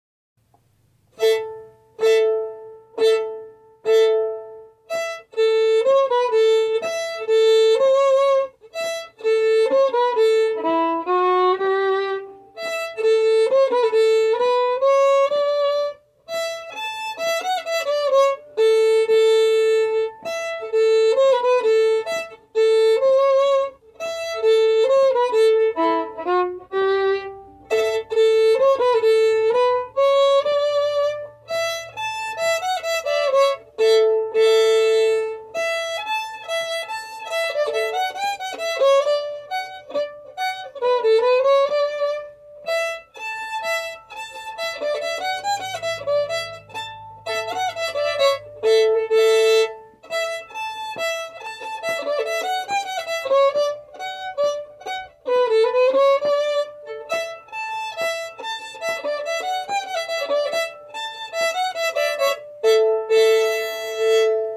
Key: D
Form: Reel
Played slowly for learning
Source: Trad.
Genre/Style: Old-time